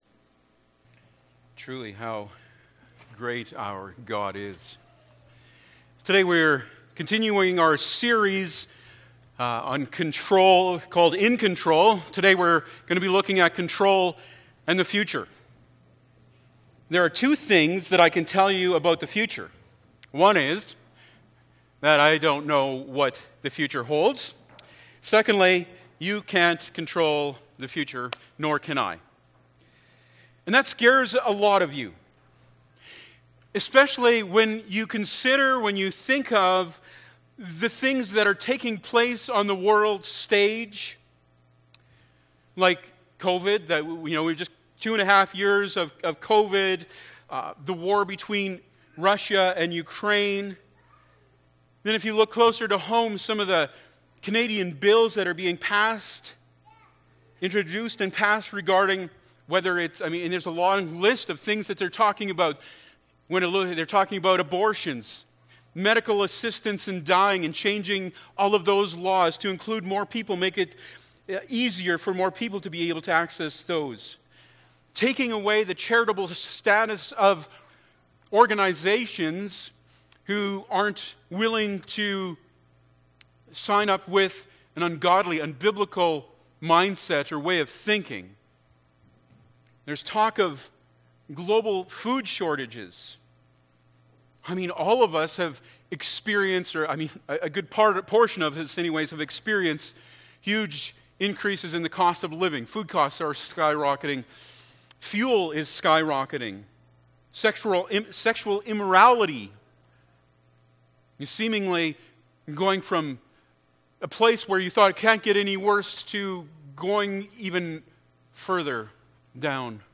Luke 12:35-40 Service Type: Sunday Morning Bible Text